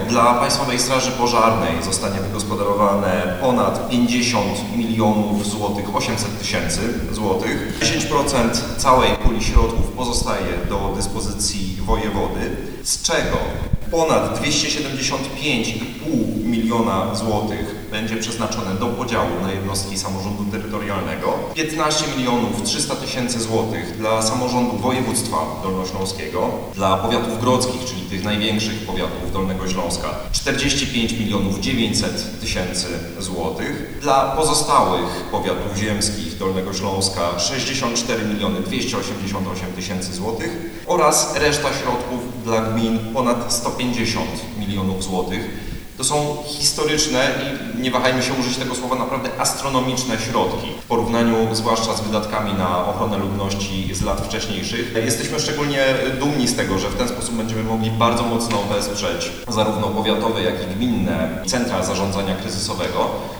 Jak dokładnie województwo planuje rozdysponować pieniądze? Tłumaczy Piotr Sebastian Kozdrowicki, wicewojewoda dolnośląski.